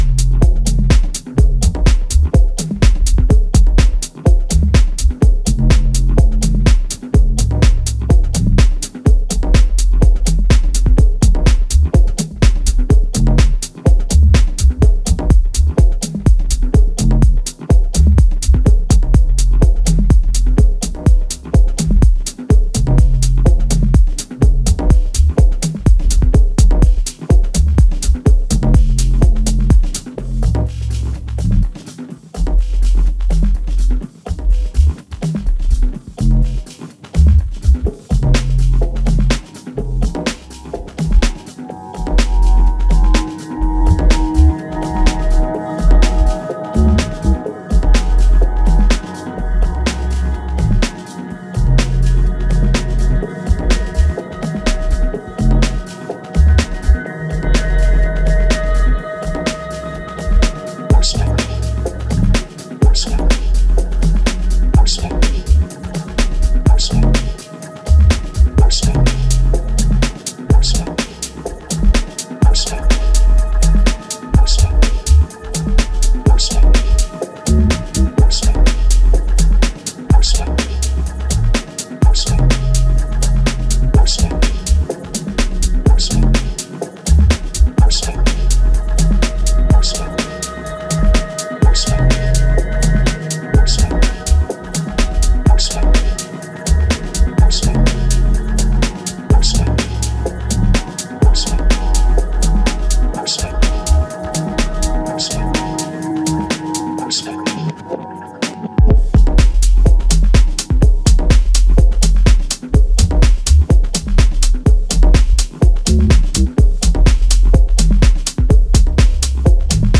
exquisitely crafted minimal dance music